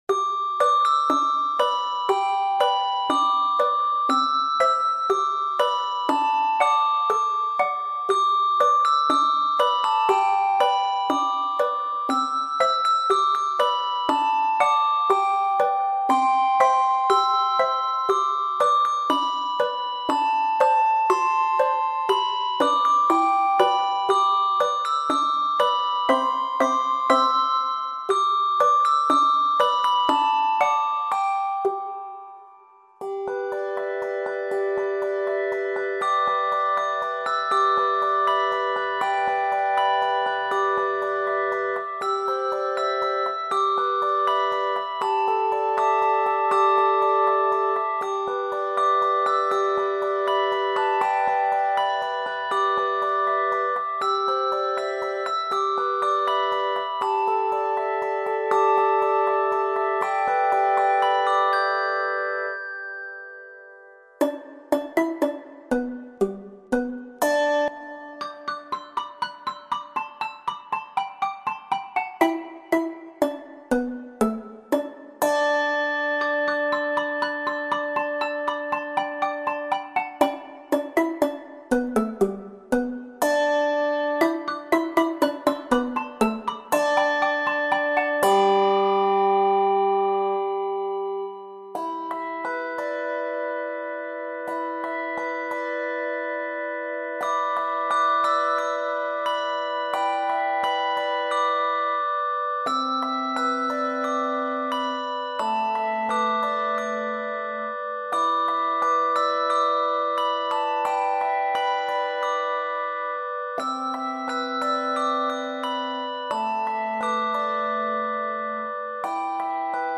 quartet setting
Less than a full handbell choir: Quartet